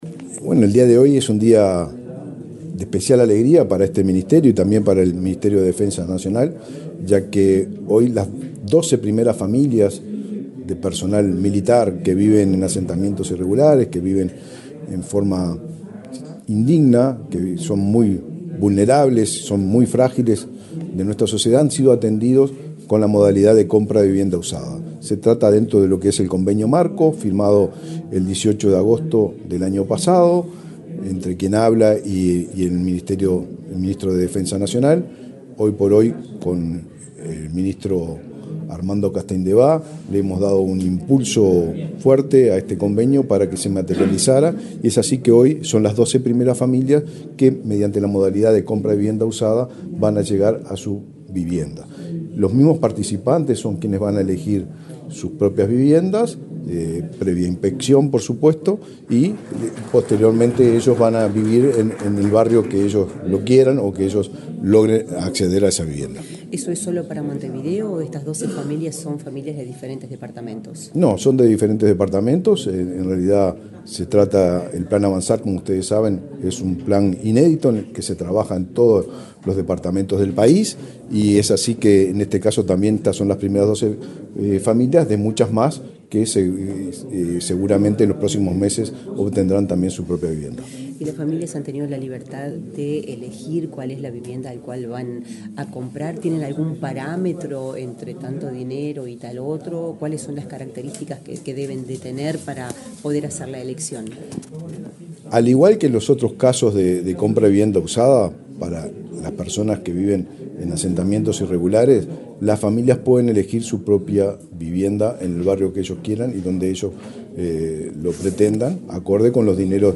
Declaraciones del ministro de Vivienda, Raúl Lozano
El ministro de Defensa Nacional, Armando Castaingdebat, y el ministro de Vivienda, Raúl Lozano, encabezaron una reunión informativa dirigida a los primeros beneficiarios del convenio entre ambos organismos para la compra de vivienda usada, en el marco del plan Avanzar, para personal militar que habita en asentamientos. Luego, Lozano dialogó con la prensa.